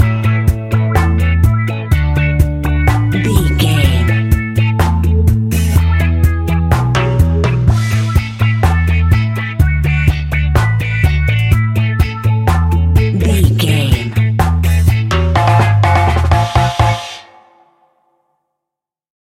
Classic reggae music with that skank bounce reggae feeling.
Uplifting
Ionian/Major
instrumentals
laid back
chilled
off beat
drums
skank guitar
hammond organ
transistor guitar
percussion
horns